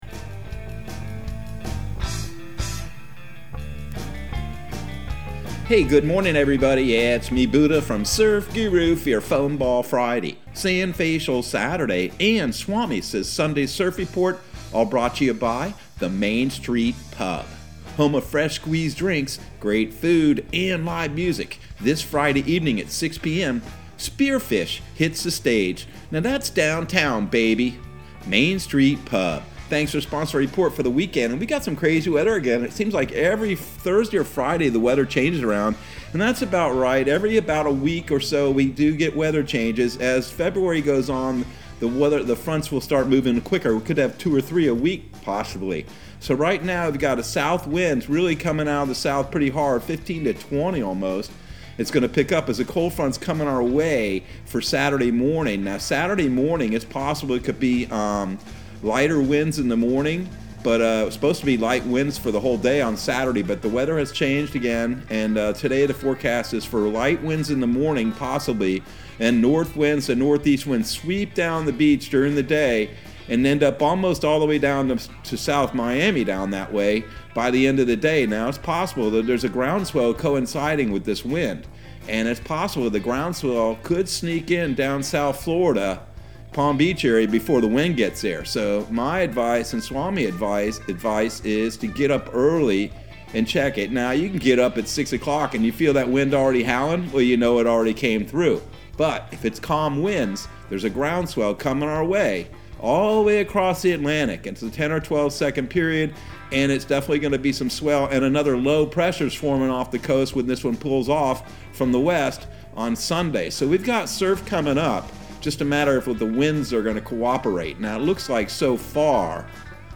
Surf Guru Surf Report and Forecast 02/04/2022 Audio surf report and surf forecast on February 04 for Central Florida and the Southeast.